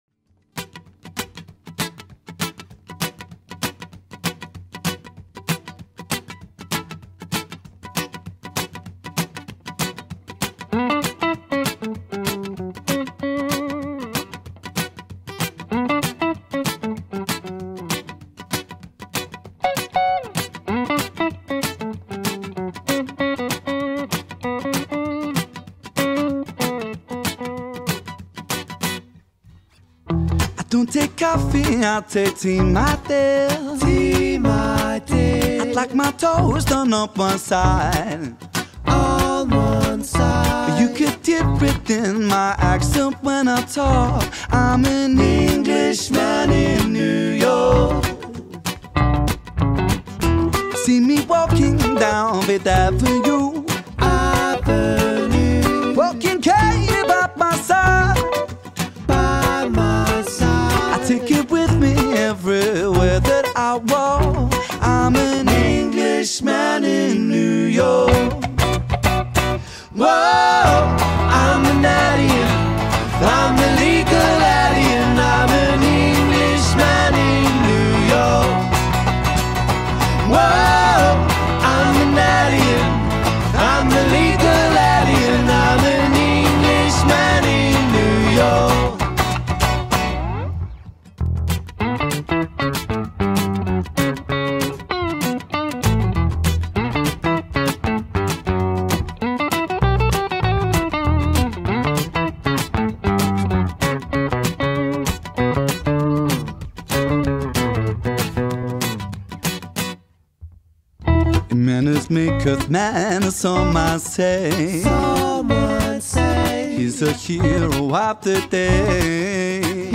ورژن آکوستیک